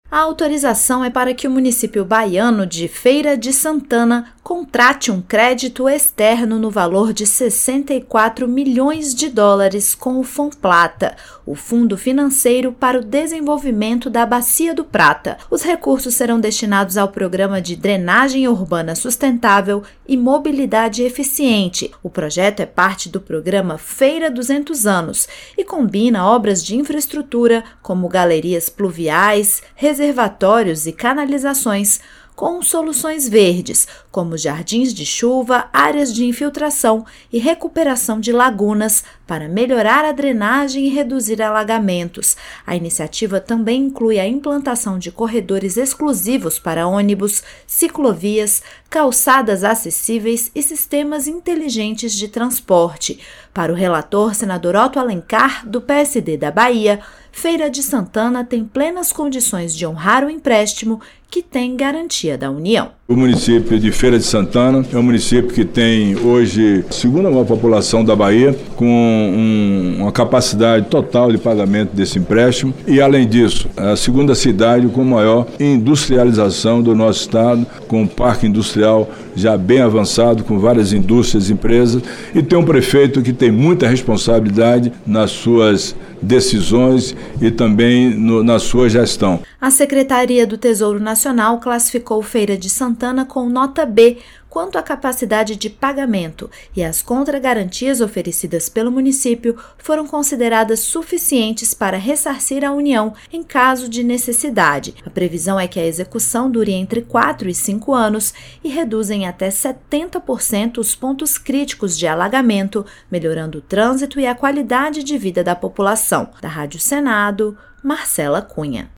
Senador Otto Alencar